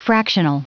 Prononciation du mot fractional en anglais (fichier audio)